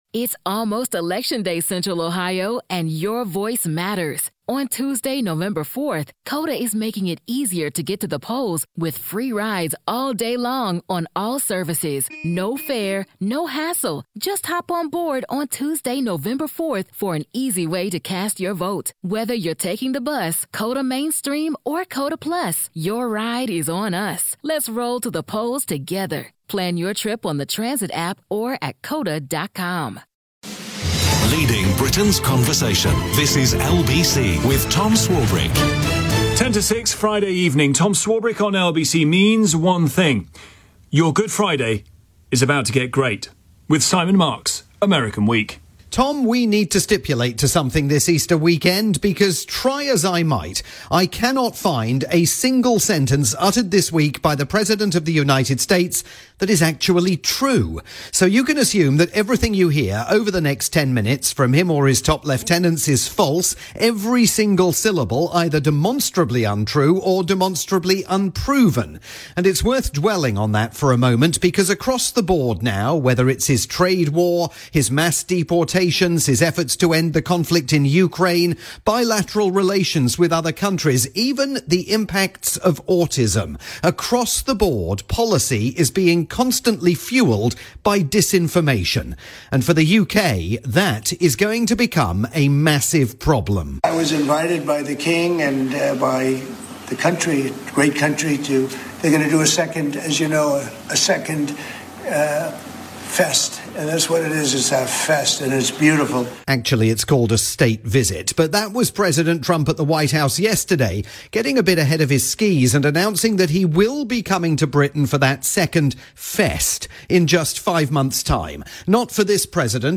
weekly chronicle of events in the United States for the Good Friday edition of Tom Swarbrick's drivetime programme on the UK's LBC.